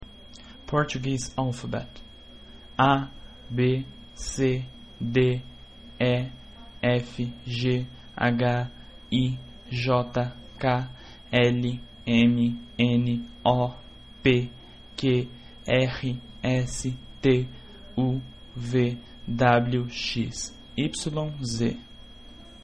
Portuguese alphabet (alfabeto português)
portuguese_alphabet.mp3